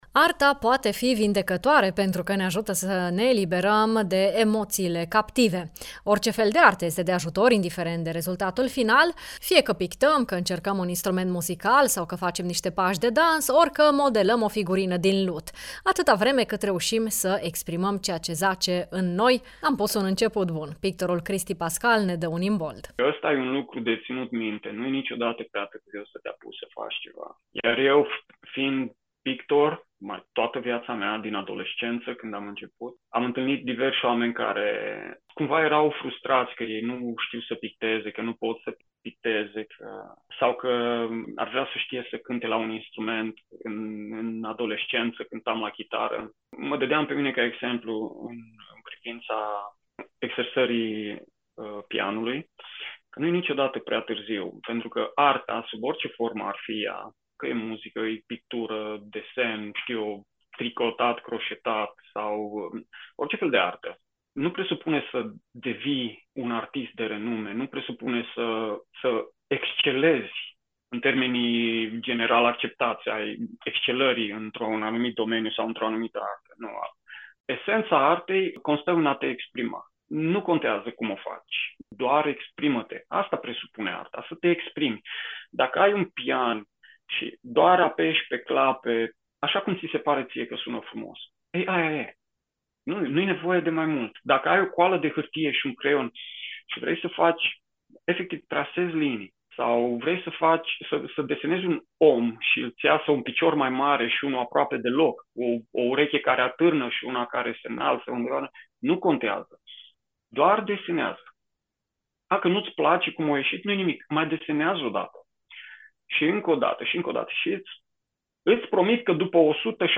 pictor